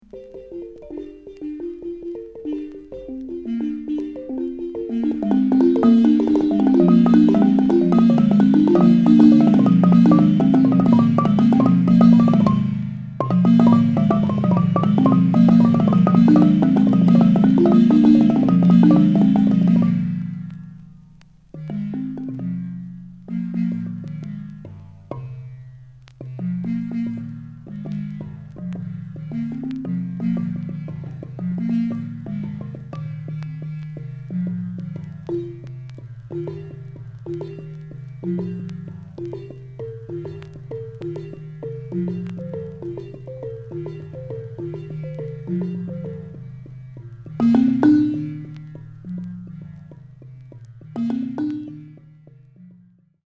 西アフリカ諸国に太古から伝わるひょうたん木琴コギリ。
ひょうたんリゾネーターの丸みのある余韻と悠久のリズムが生み出すたゆたうような陶酔感に思わずうっとりしちゃう大名盤。
キーワード：アフリカ　民族音楽　創作楽器